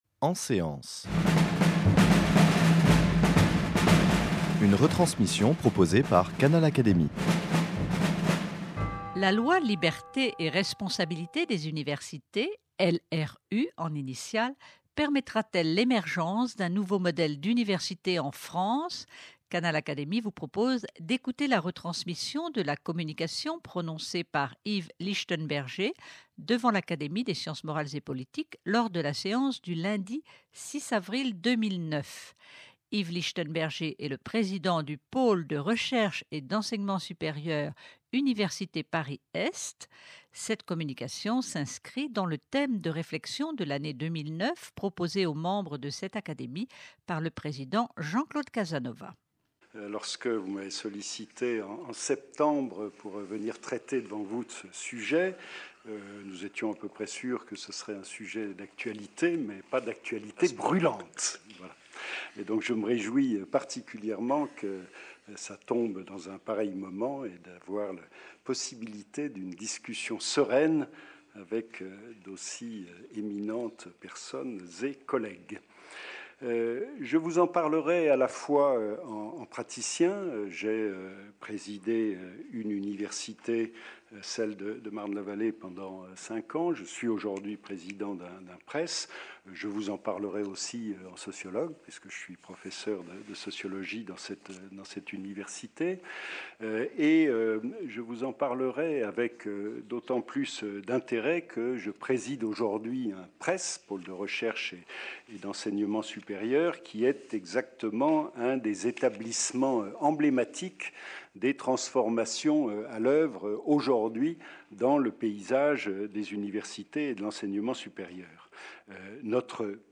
Cette communication s'inscrit dans le thème de réflexion de l'année 2009 proposée par le président Jean-Claude Casanova aux membres de l'Académie des sciences morales et politiques.